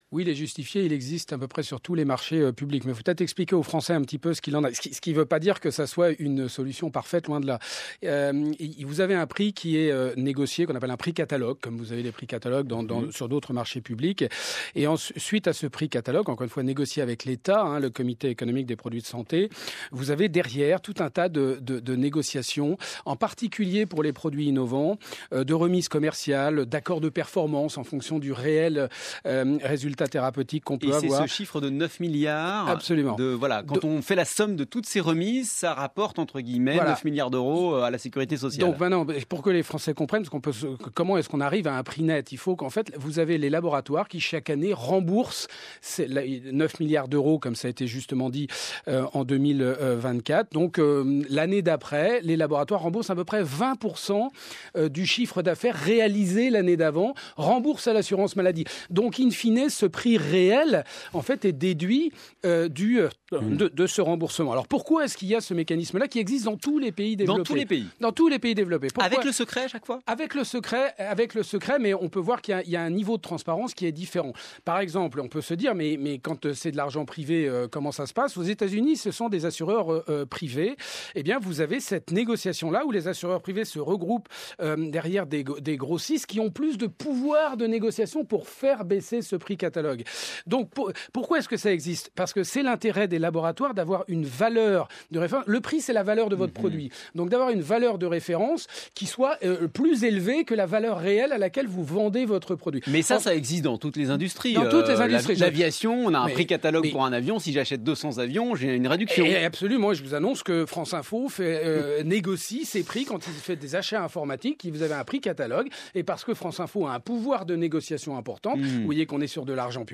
Je prenais l’exemple ce matin à France info de Radio France, la société mère de la radio négocie ses équipements informatiques à un prix inférieur au prix catalogue, ce qui économise de l’argent public.